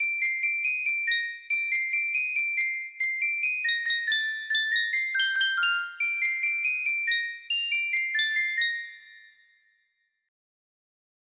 3 music box melody
Full range 60